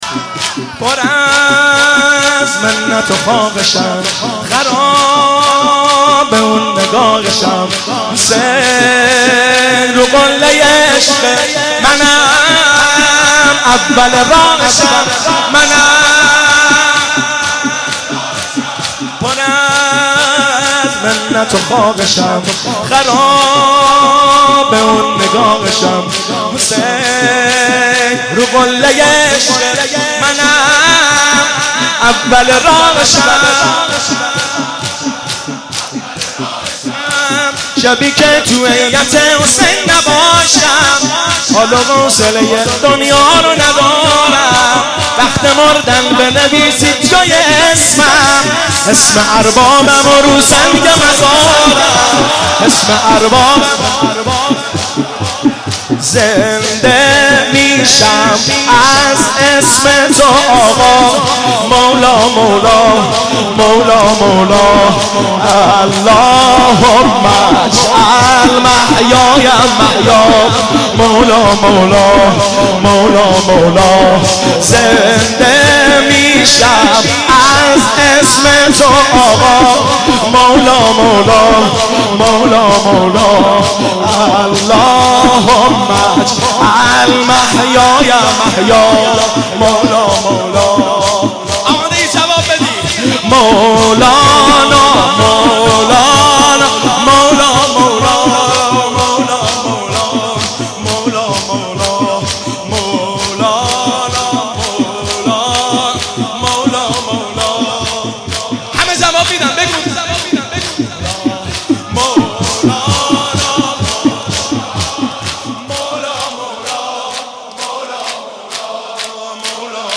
نوحه و مداحی
شور